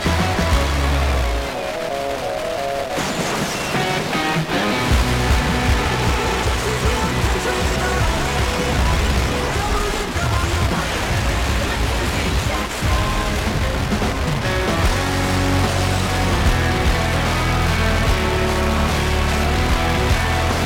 106.9 is still WWYN – signal has faded some but I did get the 8:00 ID (